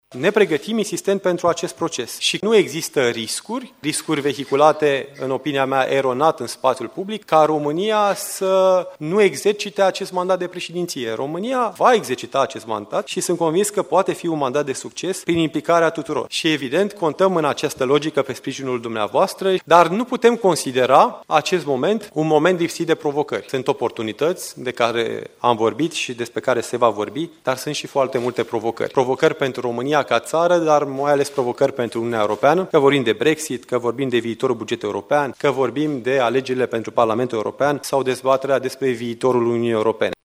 România se pregăteşte intens pentru preluarea preşedinţiei Consiliului Uniunii Europene şi nu există niciun risc ca ţara noastră să nu exercite acest mandat, a afirmat ministrul delegat pentru Afaceri Europene, Victor Negrescu. La Reuniunea anuală a diplomaţiei române desfăşurate la Bucureşti, Victor Negrescu a cerut sprijinul reprezentanţilor corpului diplomatic pentru ca exercitarea acestui mandat să fie un succes pentru ţara noastră.